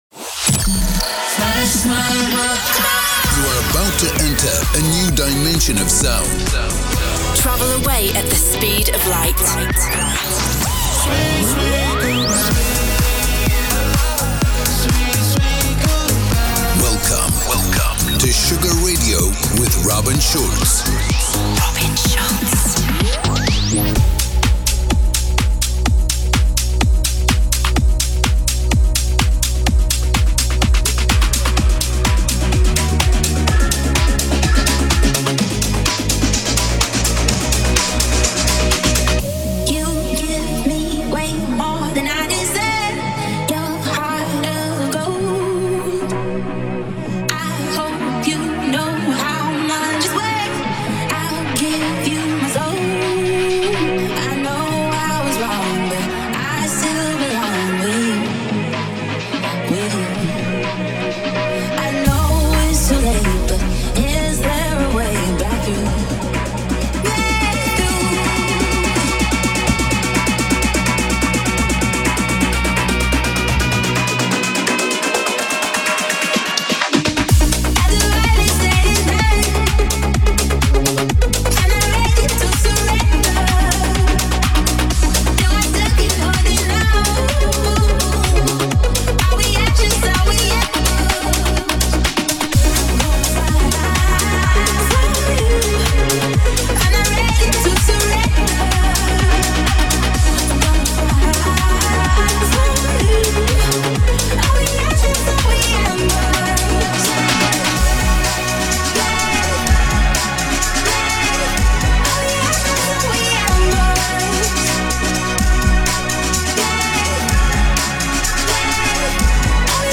music DJ Mix in MP3 format
Genre: Electro Pop